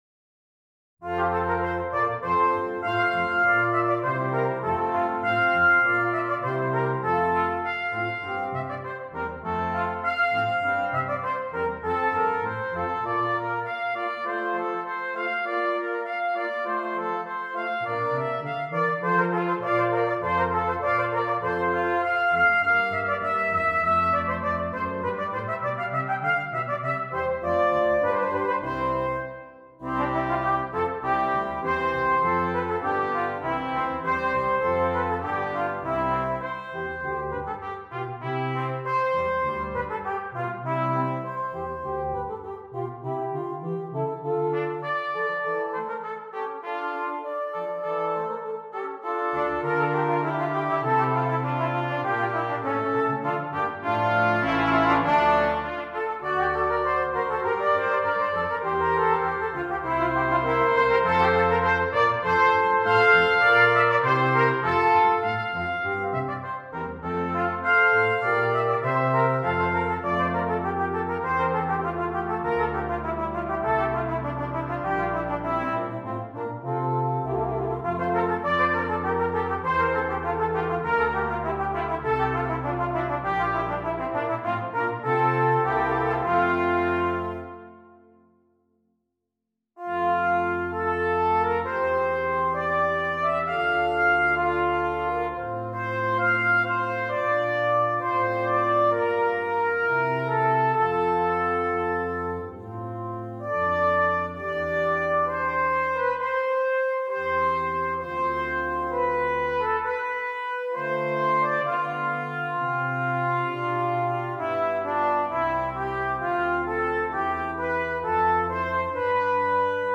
Brass Quintet and Solo Trumpet or Trombone